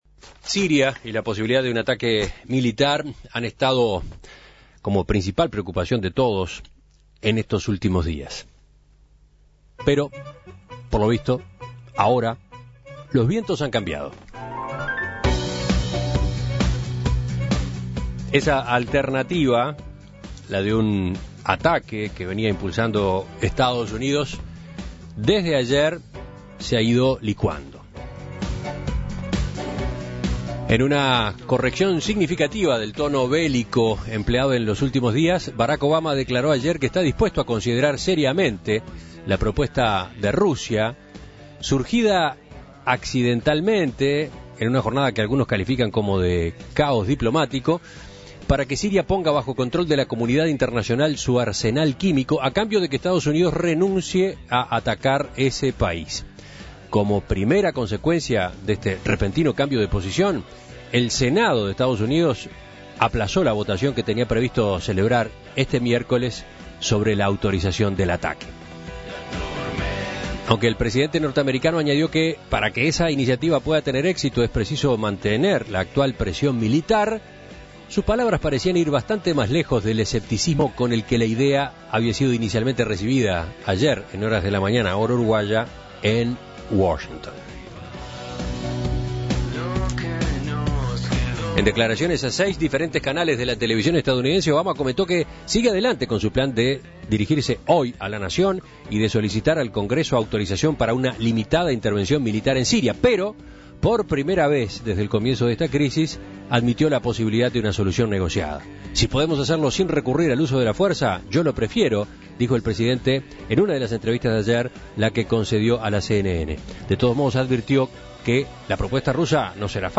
desde Turquía.